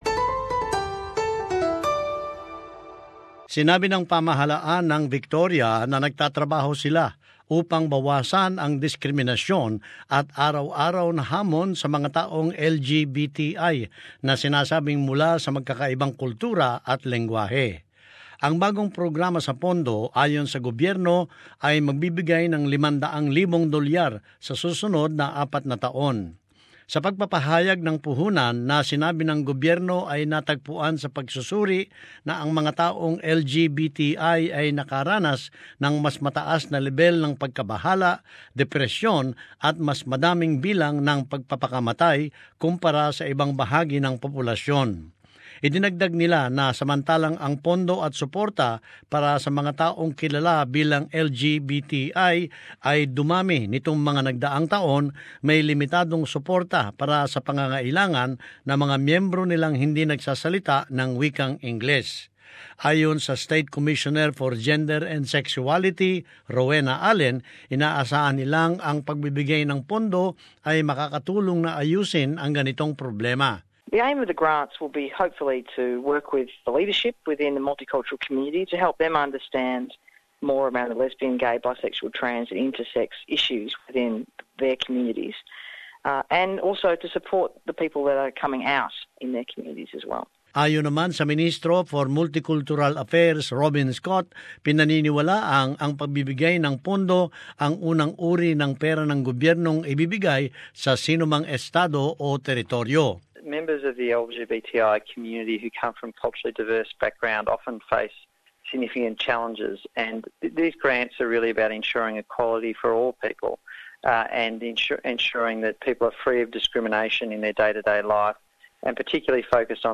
As this report shows, the L-G-B-T-I Multicultural grants are believed to be the first of their kind in Australia.